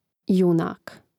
jùnāk junak